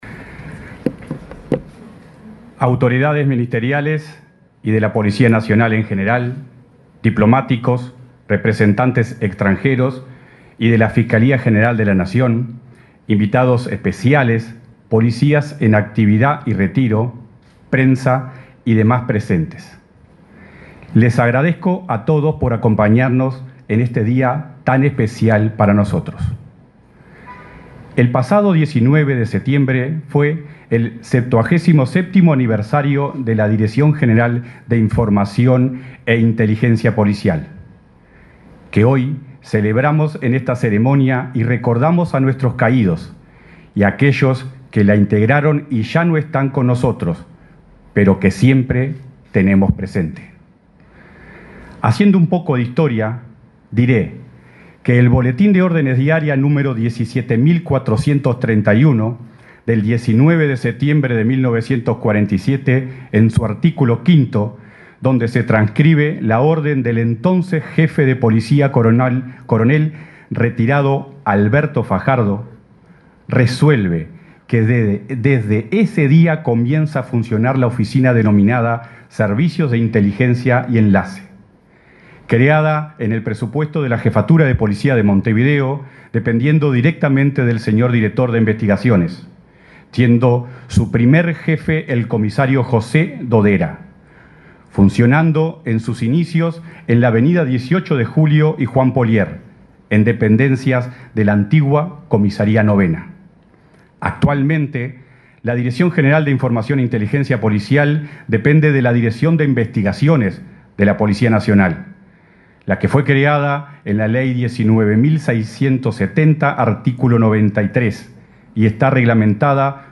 Palabras del director general de Información e Inteligencia Policial, Alejandro Sánchez 24/09/2024 Compartir Facebook X Copiar enlace WhatsApp LinkedIn El director general de Información e Inteligencia Policial, Alejandro Sánchez, hizo uso de la palabra en el acto conmemorativo del 77.° aniversario de creación de esa dependencia del Ministerio del Interior.